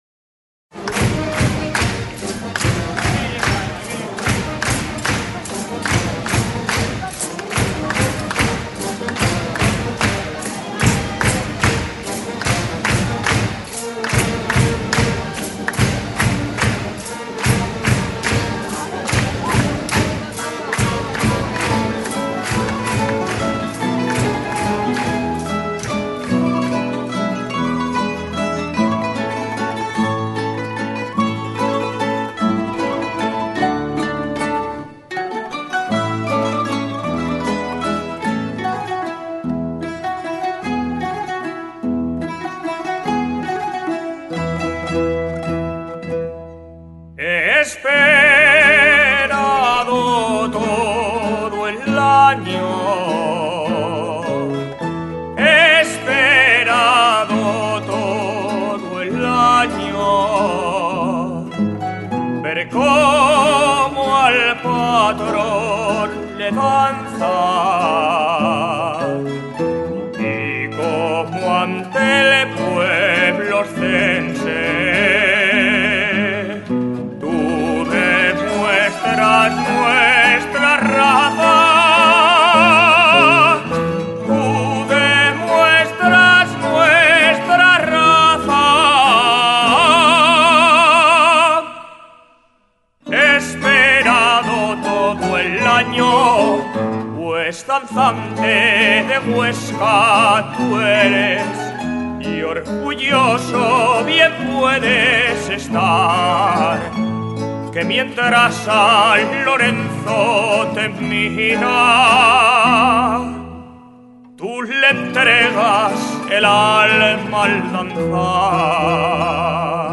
tres jotas aragonesas